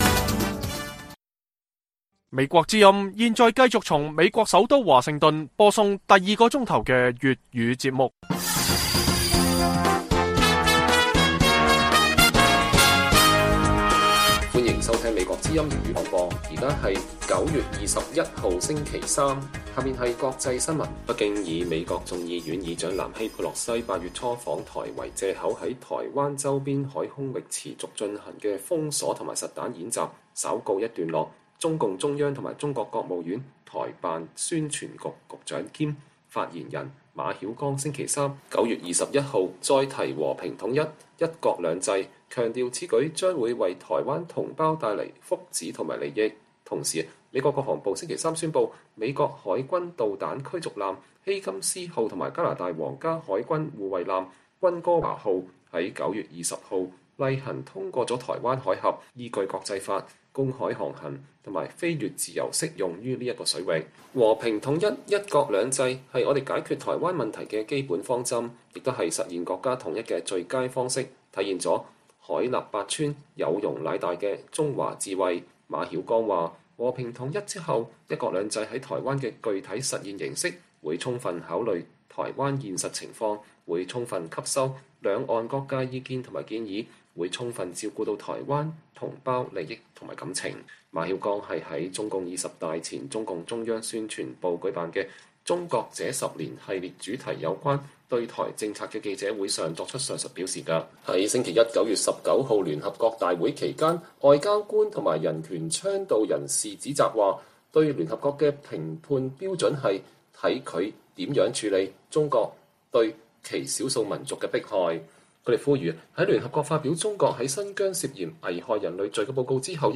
粵語新聞 晚上10-11點: 美加軍艦穿越台海自由航行，北京重提“和平統一”